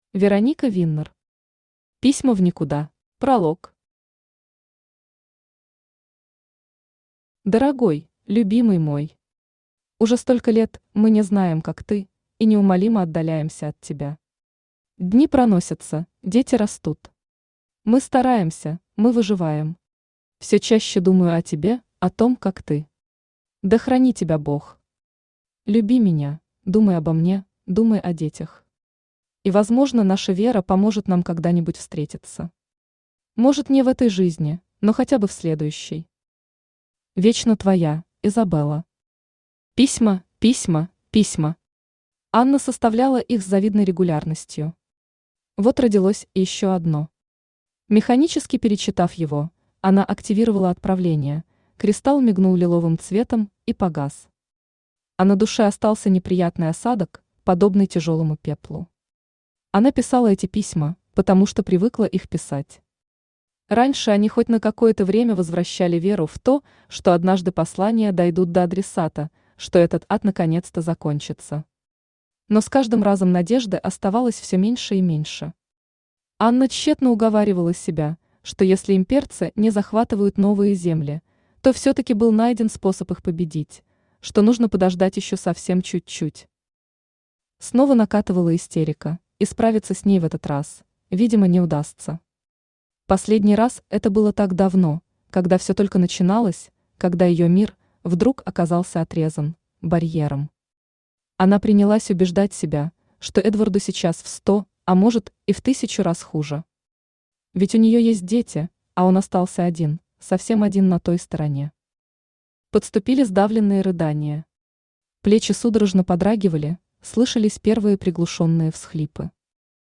Аудиокнига Письма в никуда | Библиотека аудиокниг
Aудиокнига Письма в никуда Автор Вероника Виннер Читает аудиокнигу Авточтец ЛитРес.